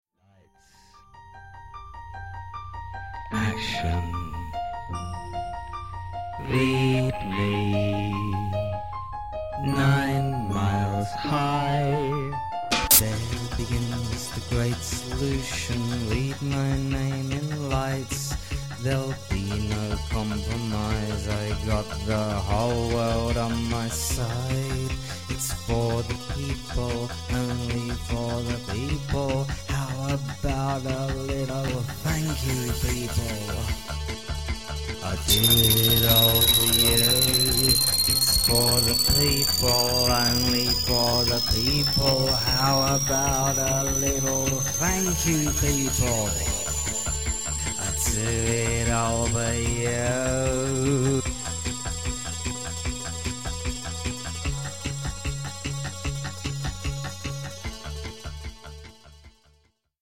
voice + instruments
disturbingly groovy